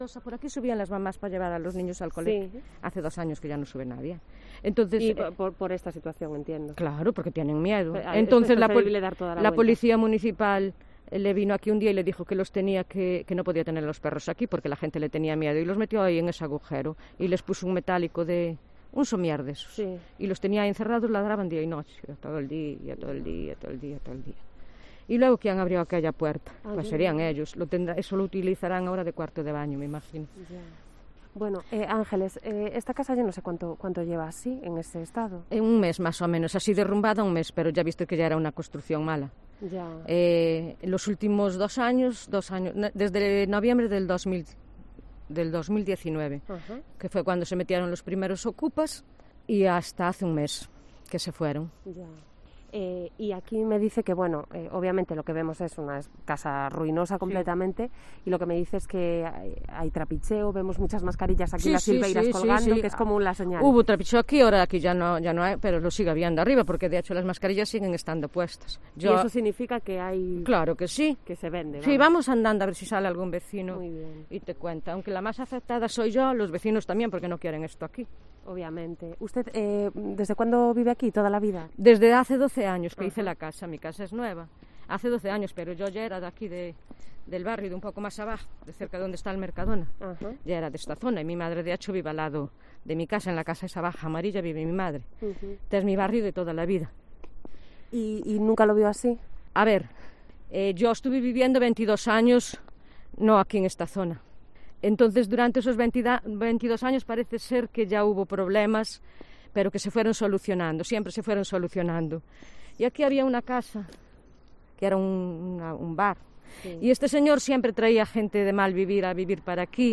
Hacemos un recorrido por Conxo de Arriba, con el problema de ocupación